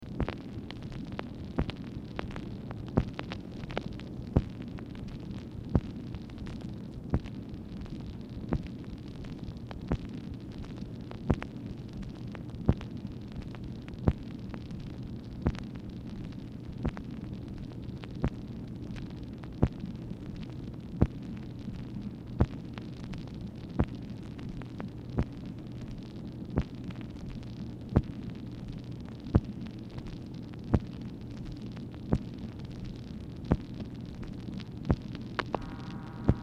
Telephone conversation # 7676, sound recording, MACHINE NOISE, 5/14/1965, time unknown | Discover LBJ
Format Dictation belt
Specific Item Type Telephone conversation